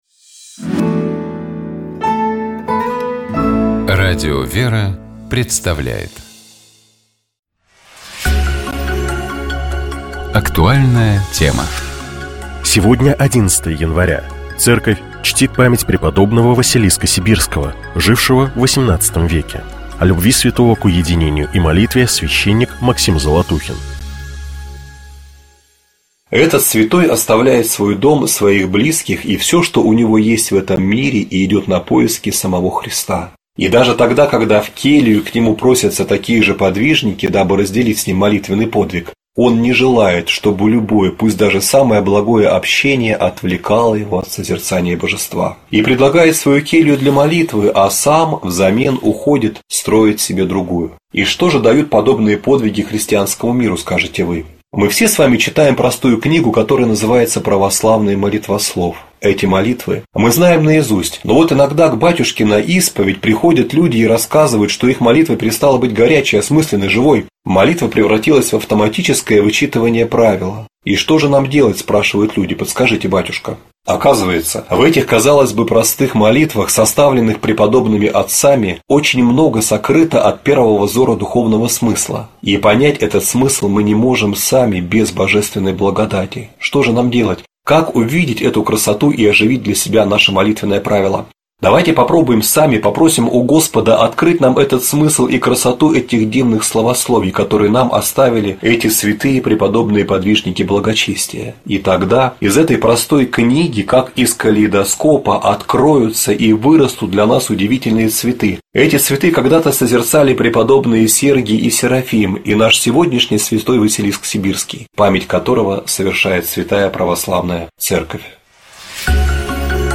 священник